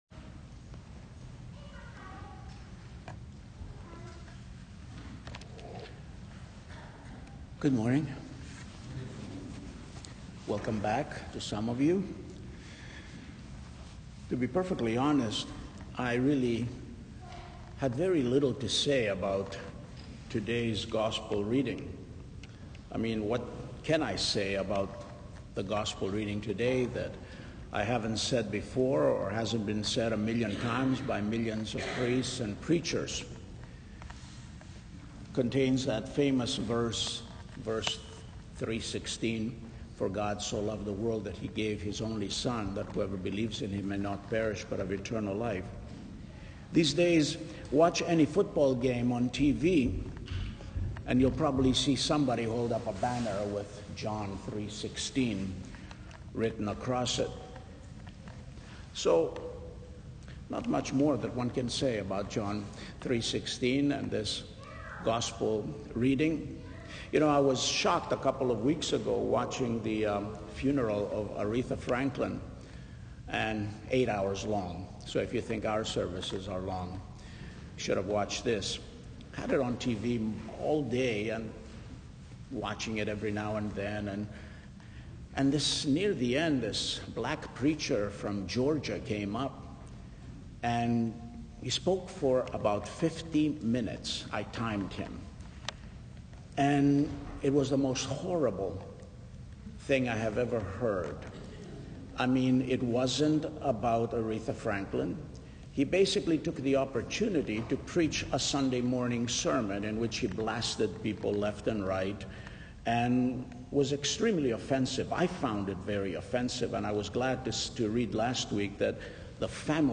A Sermon not on John 3:16